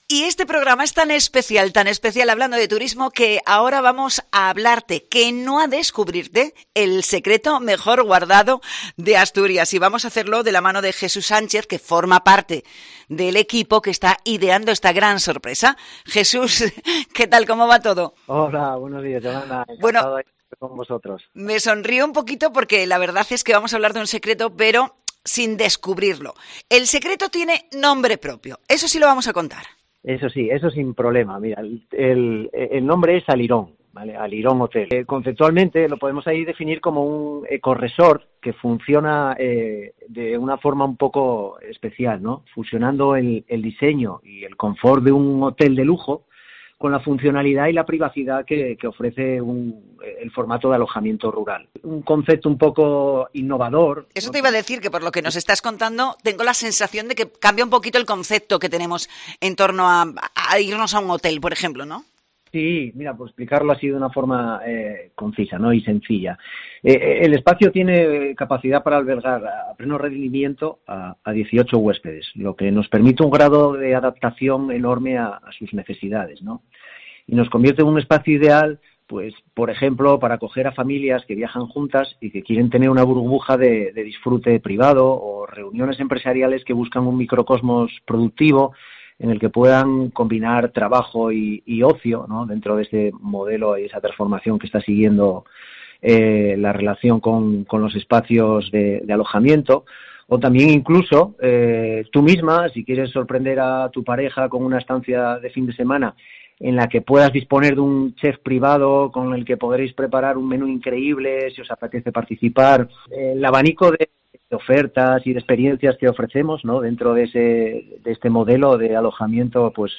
COPE ASTURIAS EN FITUR
Fitur 2022: Entrevista